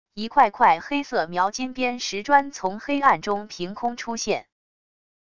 一块块黑色描金边石砖从黑暗中凭空出现wav音频